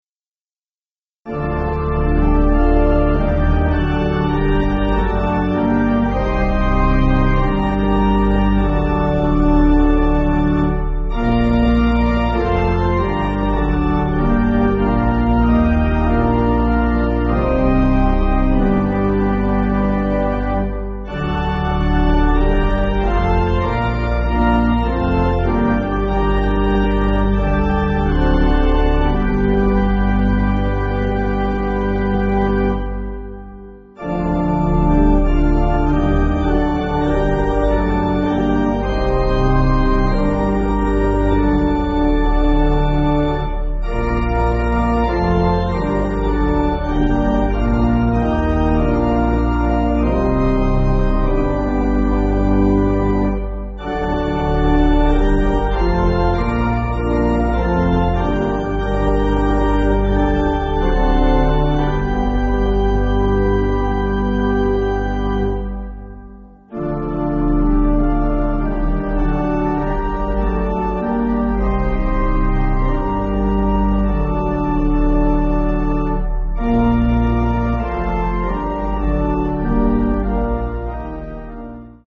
Organ
(CM)   4/Gm